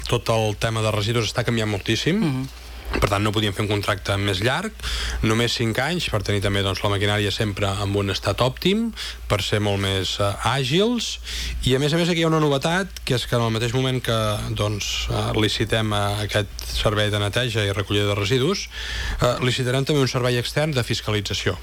L’alcalde també ha avançat alguns detalls del nou contracte, que es preveu aprovar al ple municipal a finals d’octubre per treure’l a concurs públic tot seguit. Una de les principals novetats serà la reducció de la durada de l’adjudicació dels 12 anys actuals a només 5.